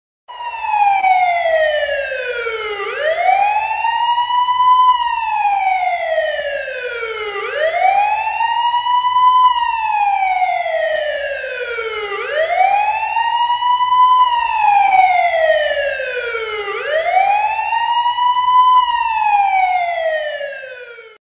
Sirena+De+Policia+(Efecto+De+Sonido) (audio/mpeg)